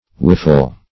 Whiffle \Whif"fle\, v. i. [imp.